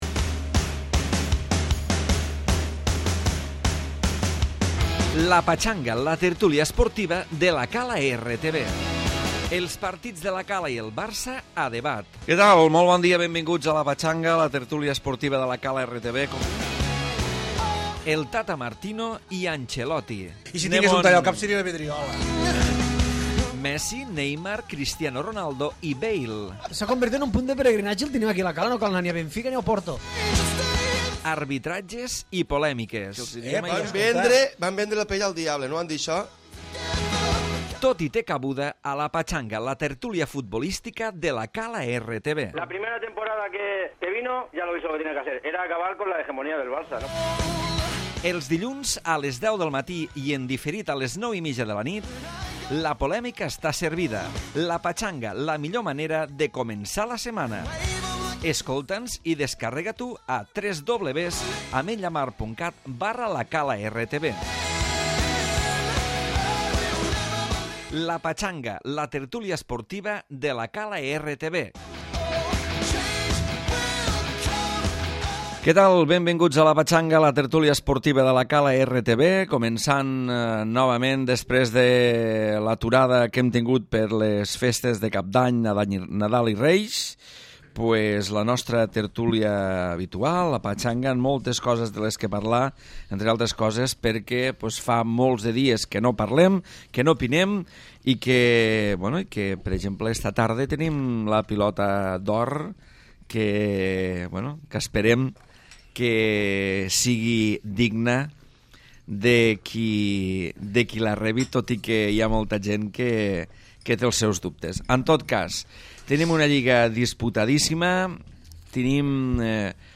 Tertúlia esportiva i futbolistica